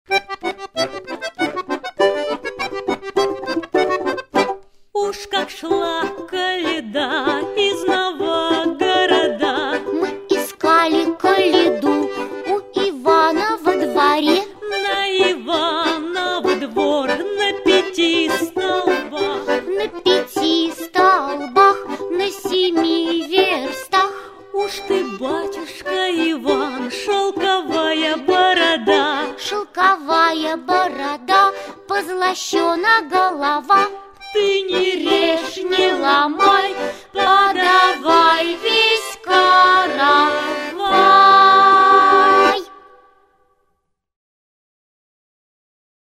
Слова и музыка народные.